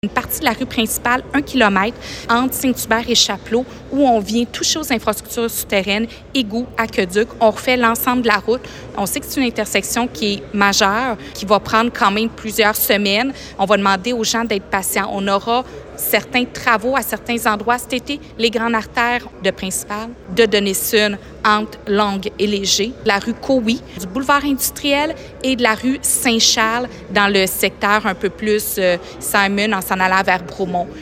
Julie Bourdon , mairesse de Granby :